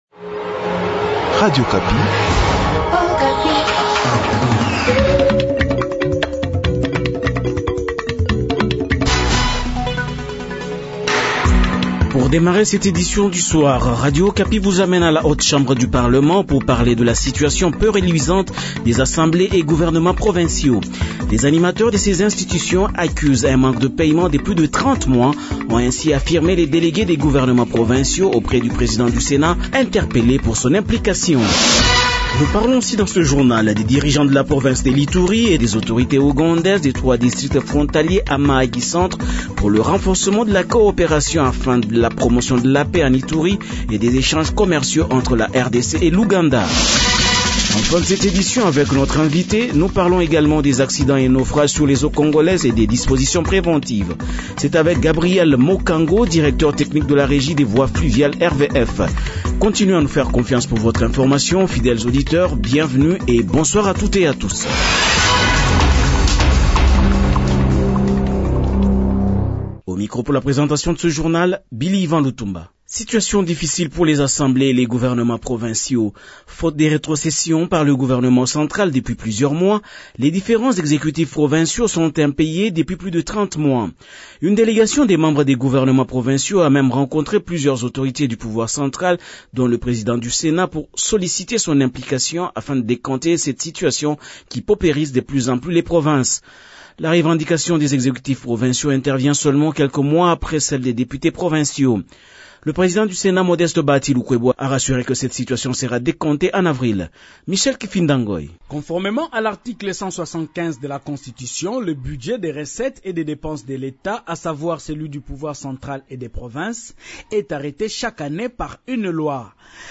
Journal Soir
Journal 18h du jeudi 10 mars 2022